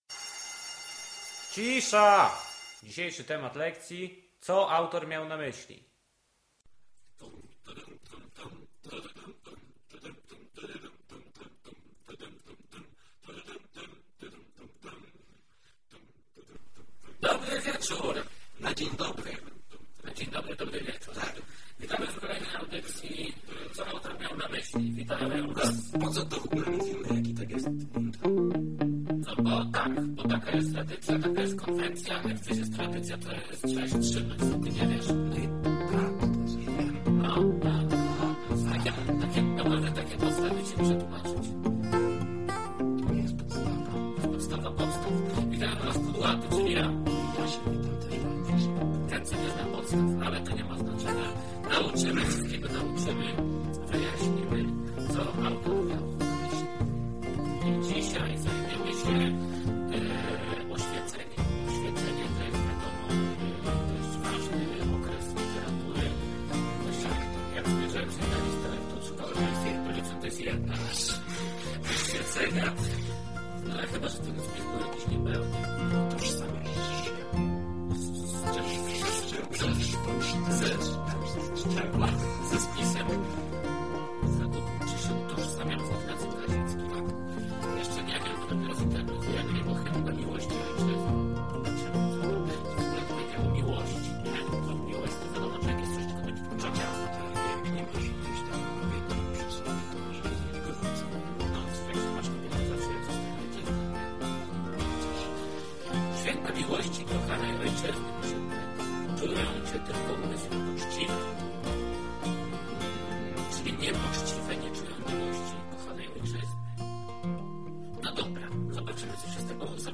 "Co Ałtor Miał na Myśli" to audycja rozrywkowa, nagrywana co tydzień lub dwa.